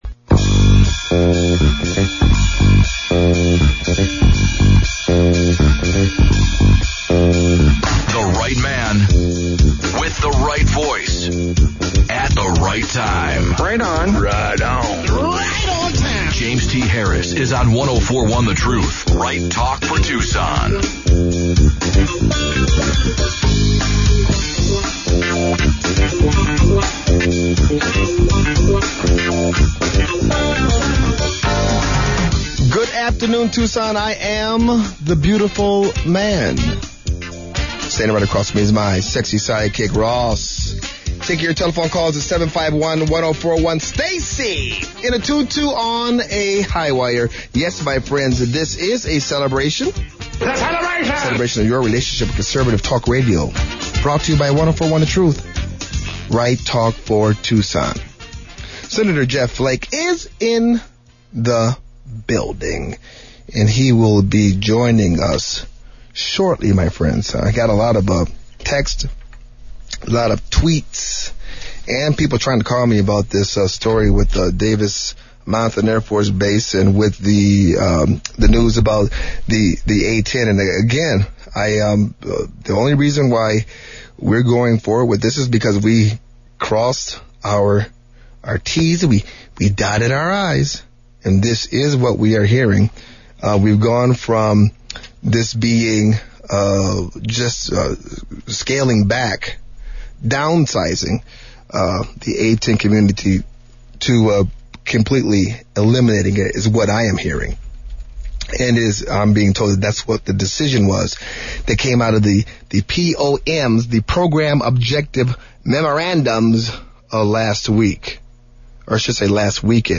FULL-JEFF-FLAKE-INTERVIEW-JTH-8-15-13.mp3